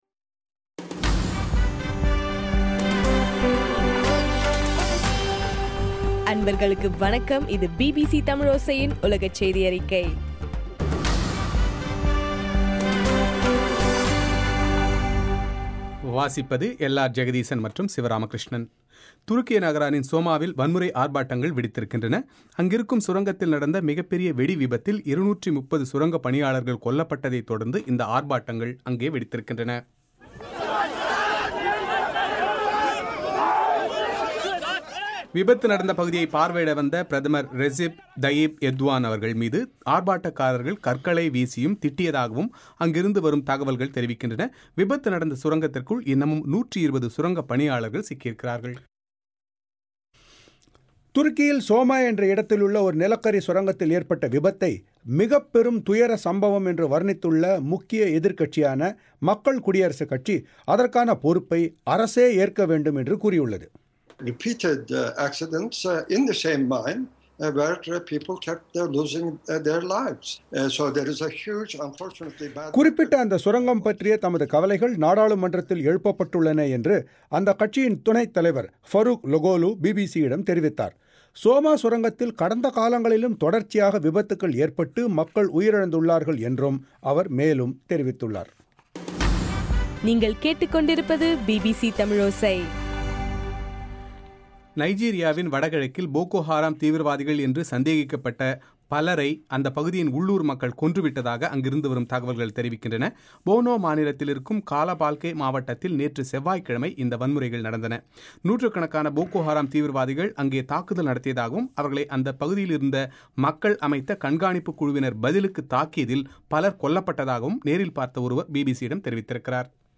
மே 14 - பிபிசியின் உலகச் செய்திகள்